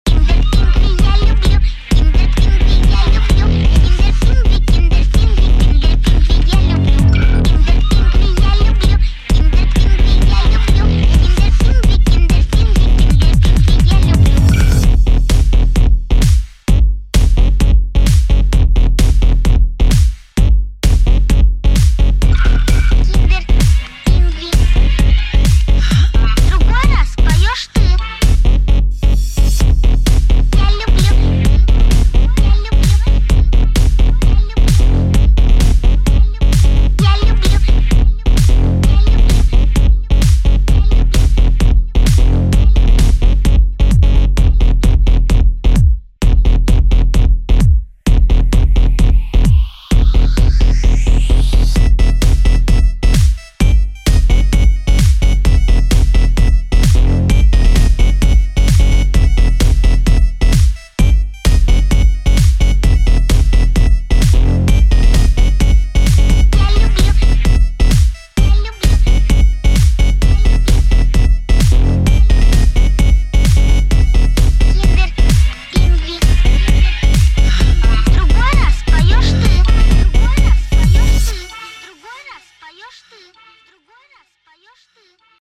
Прикол микс) любителям электро!)
electro-house